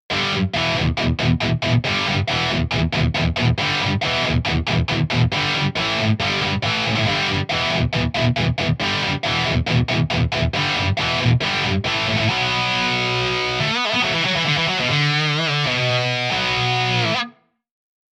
Sound examples for Voxengo Stereo Touch - Free Stereo-Widener Effect Plugin
Stereo Touch - Rock Guitar - Narrow Space.mp3